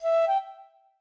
flute_eg.ogg